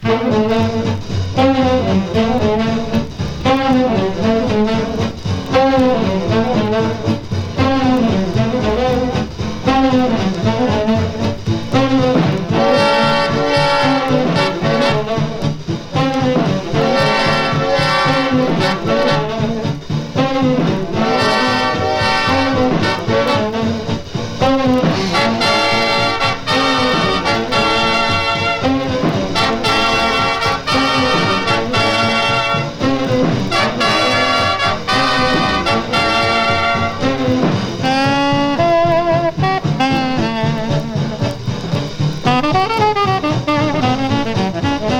Jazz, Blues, Jump Blues　USA　12inchレコード　33rpm　Mono